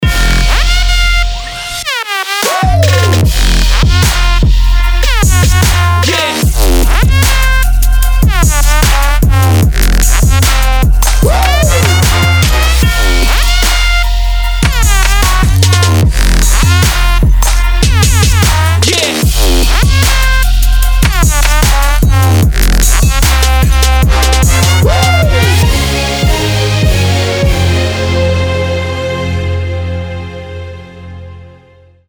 • Качество: 320, Stereo
Хип-хоп
Trap
Лютый Хип-Хоп и Трэп!